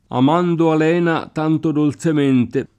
am#ndo al$na t#nto dolZem%nte] (Guido Orlandi) — cfr. Sant’Elena; santelena